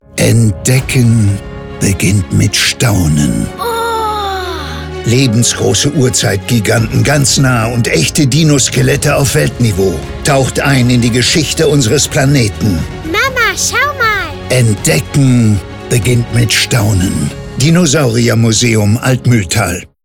spot-off-dinosaurier museum